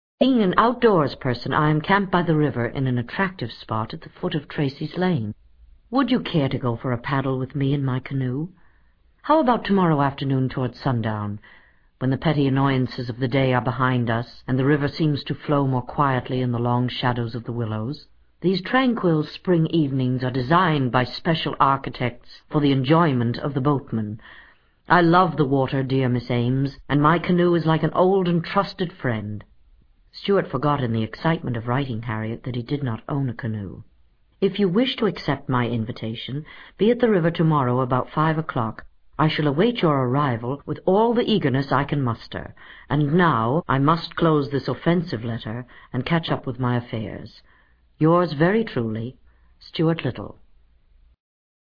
在线英语听力室精灵鼠小弟 第74期:相约黄昏后的听力文件下载, 《精灵鼠小弟》是双语有声读物下面的子栏目，是学习英语，提高英语成绩的极好素材。